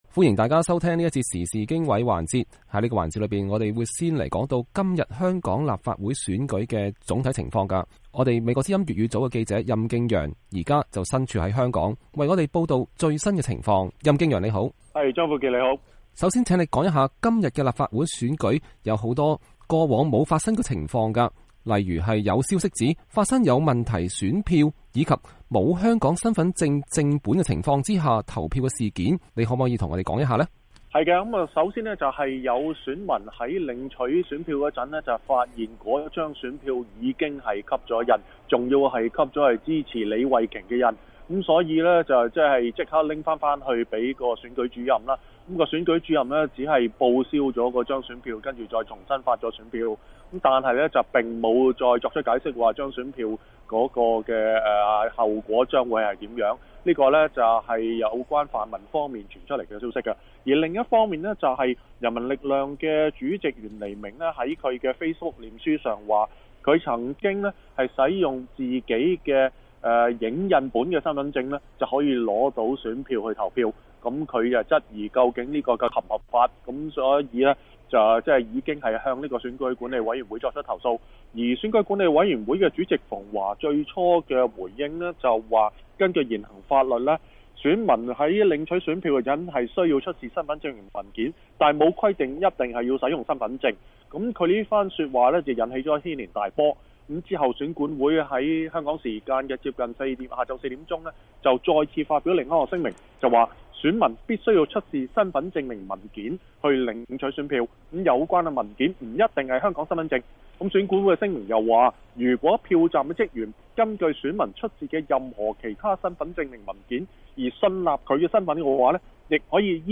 下面我們與他談一談今天的情況。